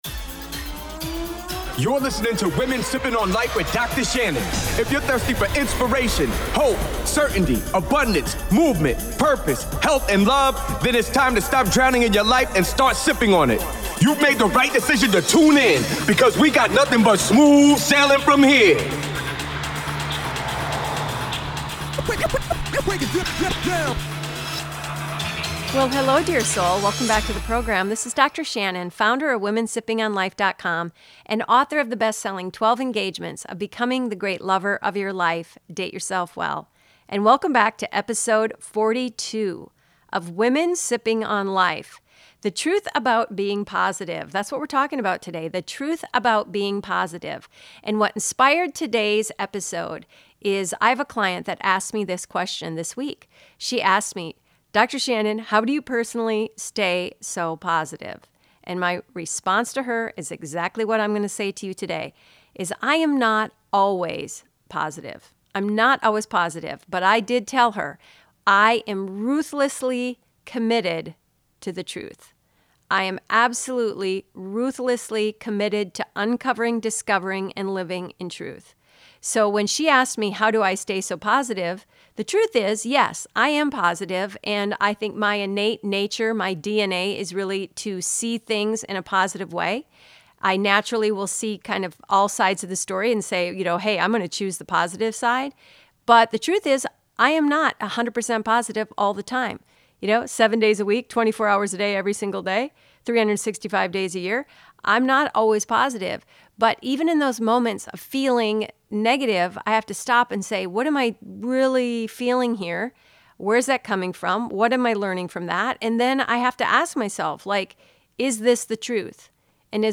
Outro music